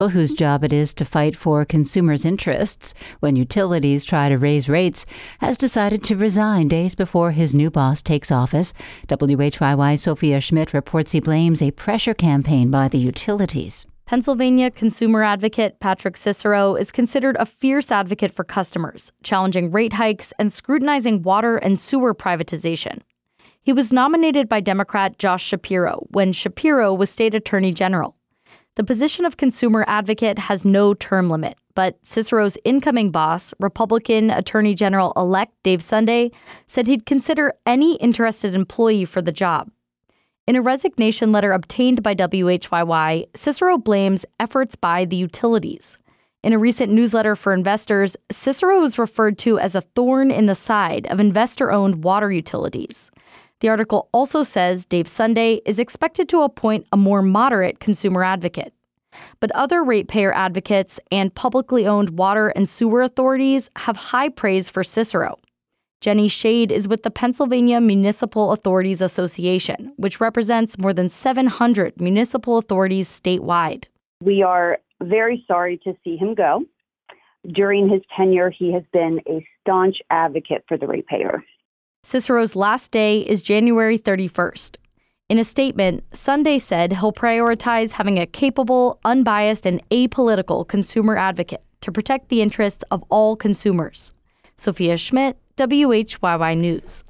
Patrick Cicero speaking